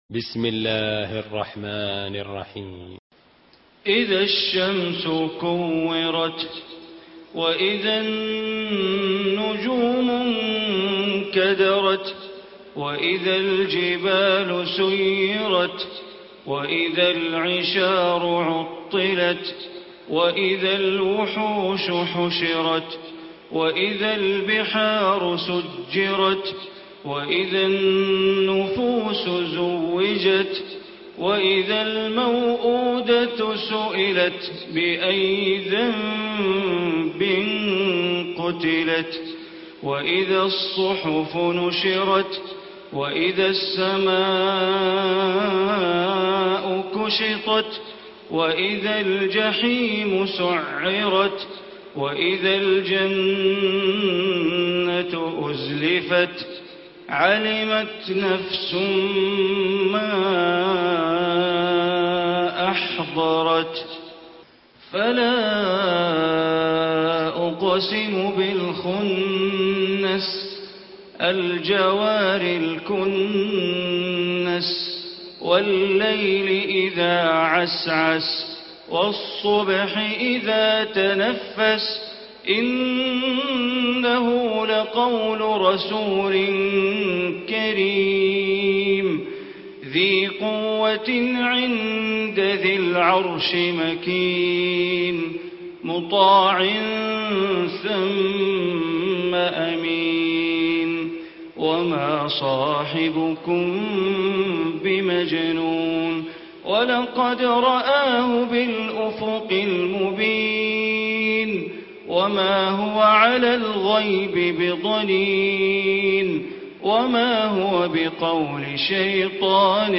Surah Takwir Recitation by Sheikh Bandar Baleela
Surah Takwir, listen online mp3 tilawat / recitation in Arabic recited by Imam e Kaaba Sheikh Bandar Baleela.